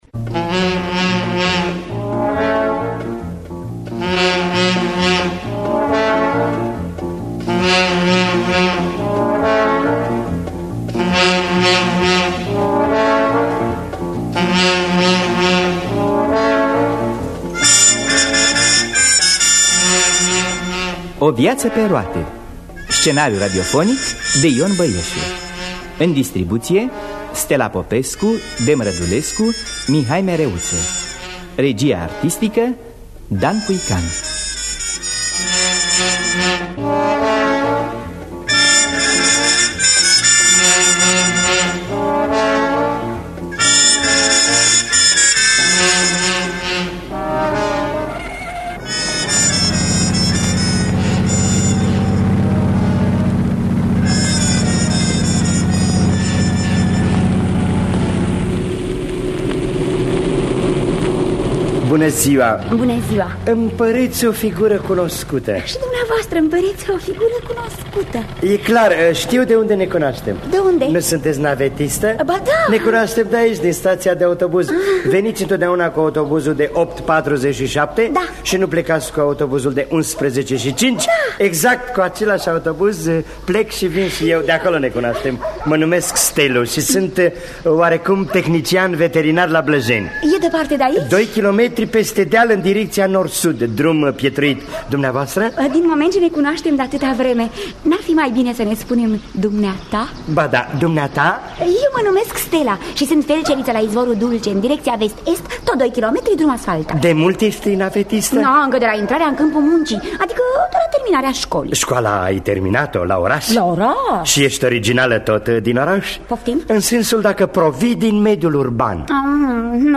O viață pe roate de Ion Băieșu – Teatru Radiofonic Online